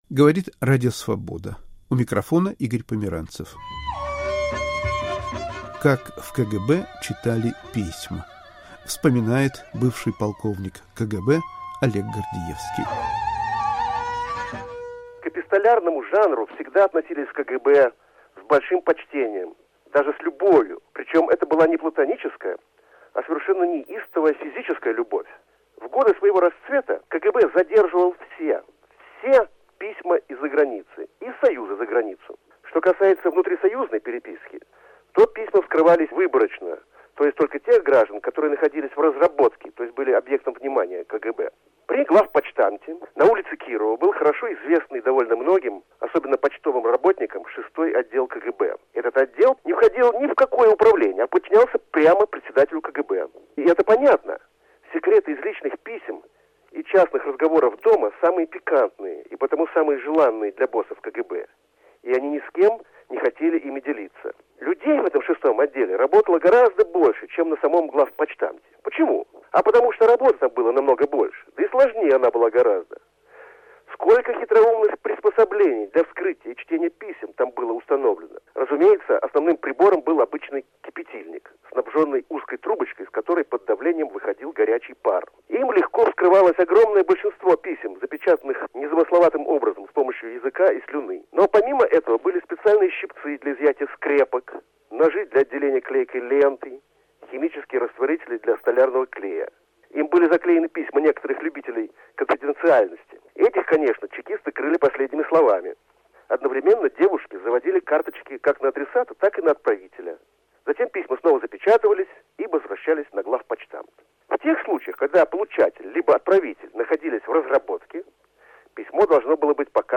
Вспоминает бывший полковник КГБ Олег Гордиевский